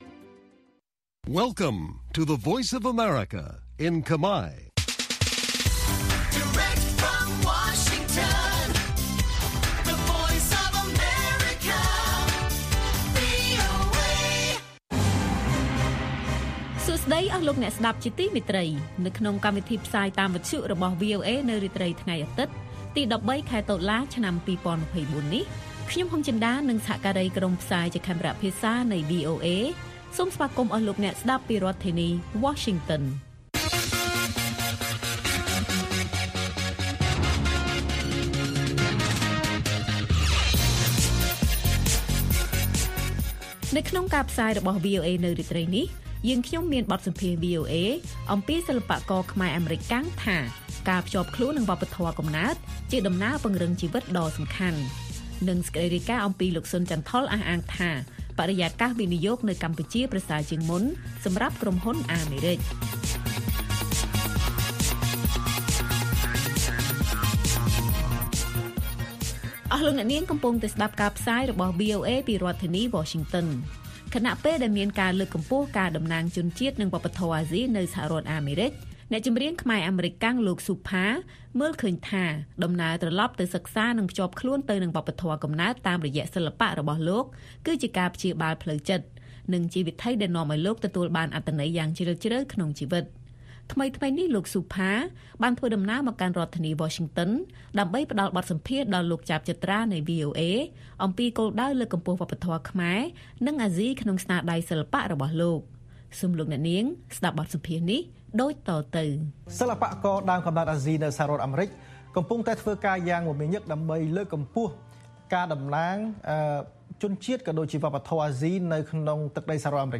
ព័ត៌មានពេលរាត្រី ១៣ តុលា៖ លោក ស៊ុន ចាន់ថុល អះអាងថា បរិយាកាសវិនិយោគនៅកម្ពុជាប្រសើរជាងមុនសម្រាប់ក្រុមហ៊ុនអាមេរិក